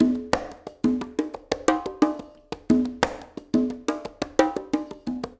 SMP CNGAS1-R.wav